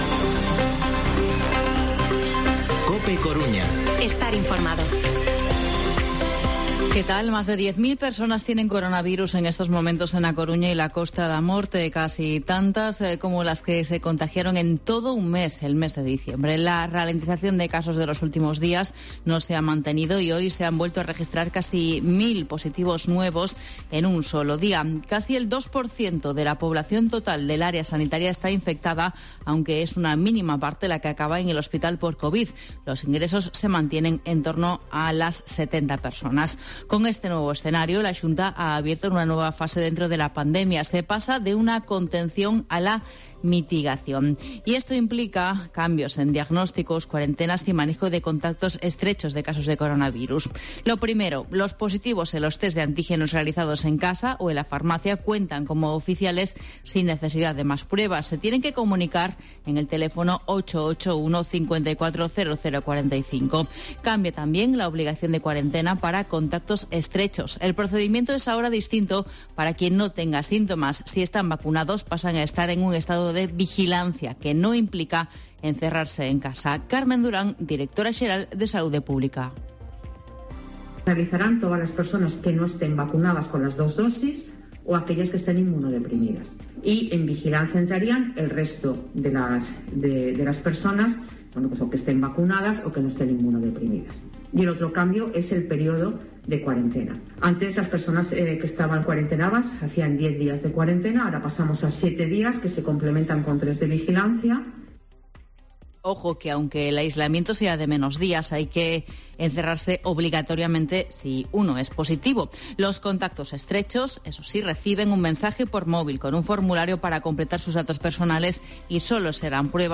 Informativo mediodía COPE Coruña miércoles, 5 de enero de 2022